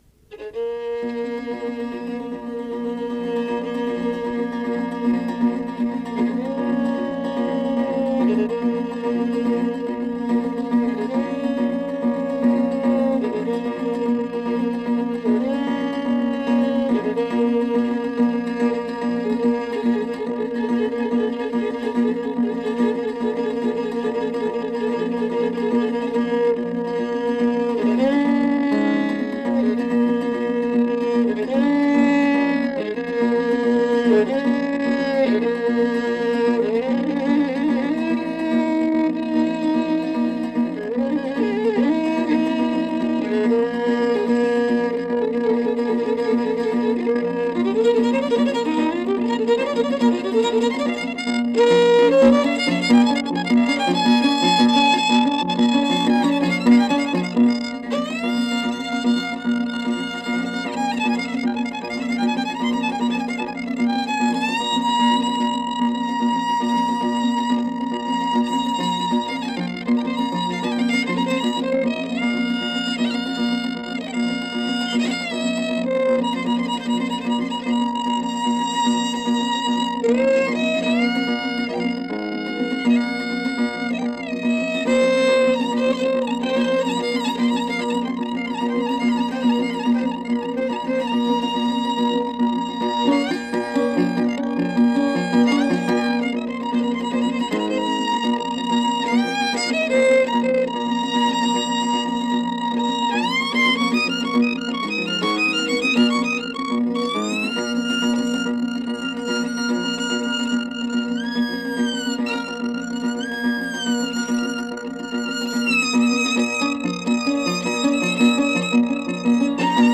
Kaba e llojit me dy pjesë: kaba dhe valle. Pjesa e parë përshfaqet si kaba me motive baritore tipike për muzikën instrumentale të minoritetit grek të Dropullit. Rëndom, kabatë dropullite kanë një percpetim virtuoz të fakturës instrumentale e cila i atribuohet talentit të interpretuesve të saj. Një kulminacion shënohet në pjesën e parë të saj kur violinisti përmes një teknike të admirueshme violinistike imiton në violinë këngëtimin e birbilit, i cili konsiderohet si simbol i kultit të muzikës. Vallja e gëzuar e përforcon atmosferën festive që sjell zakonisht pjesa e dytë e kabave.